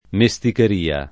mesticheria [ me S tiker & a ] s. f.